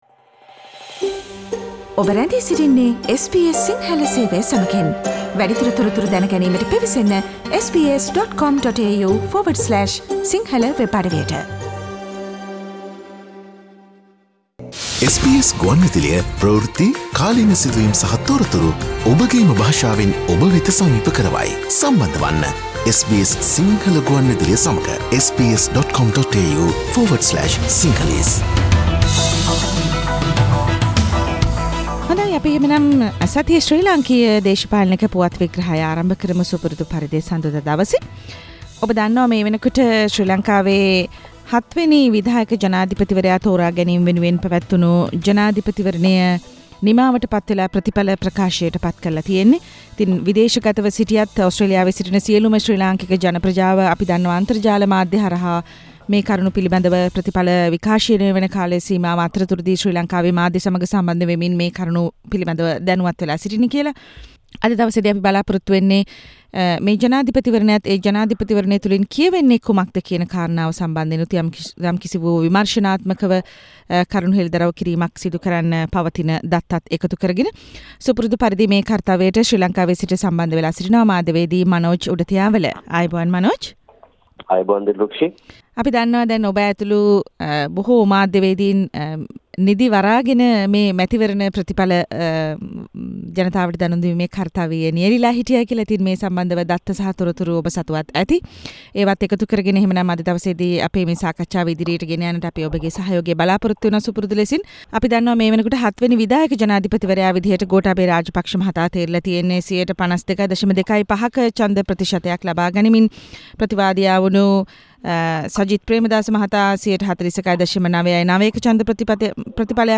මෙවර ශ්‍රී ලංකා ජනාධිපතිවරණයේ සමස්ත ප්‍රථිපලය මඟින් අපට කියන්නේ කුමක්ද? SBS සිංහල දේශපාලන පුවත් සමාලෝචනයෙන්